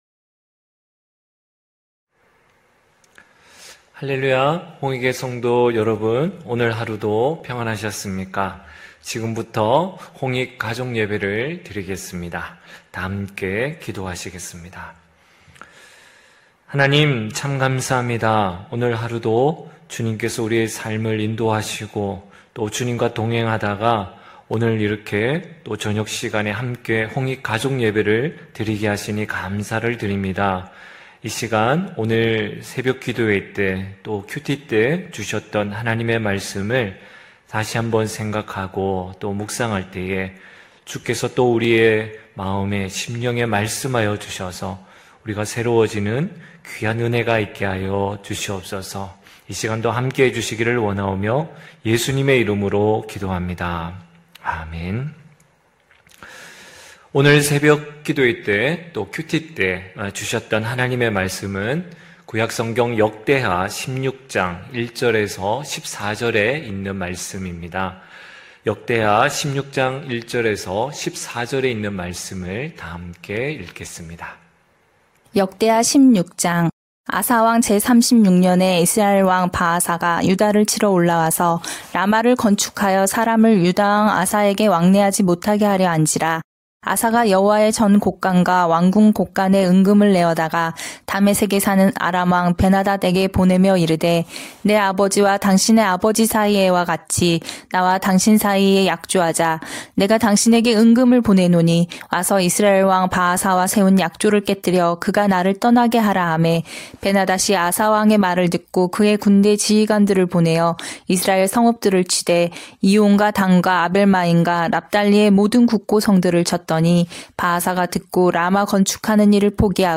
9시홍익가족예배(11월20일).mp3